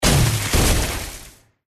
GameMpassetsMinigamesCjsnowEn_USDeploySoundGameplaySfx_mg_2013_cjsnow_impactsenseisnow.mp3